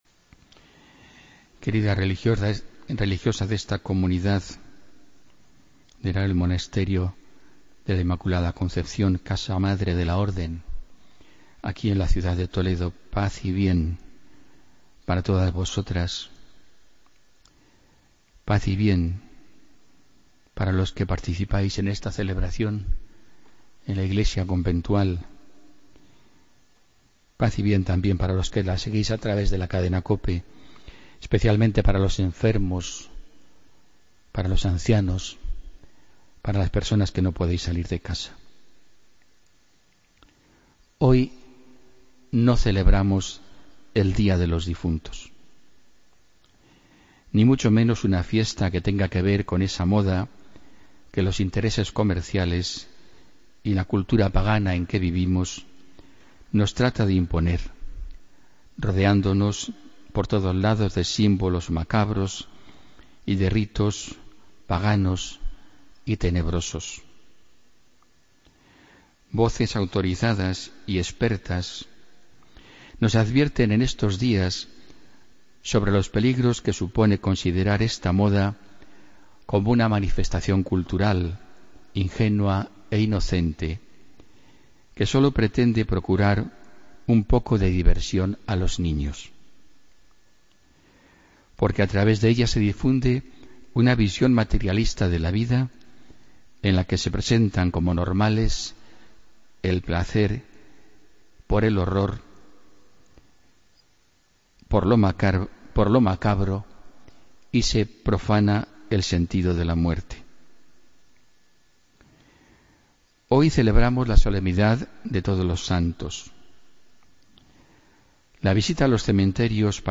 Homilía del martes, 1 de noviembre de 2016